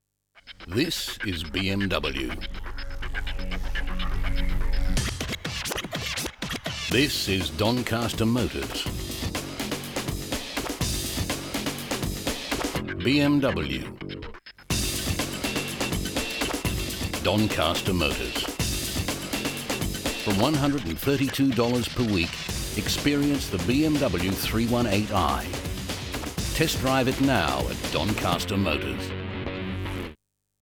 Automotive
Rode NT-1A Microphone, Focusrite interface.
BaritoneDeepLow